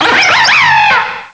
pokeemerald / sound / direct_sound_samples / cries / swoobat.aif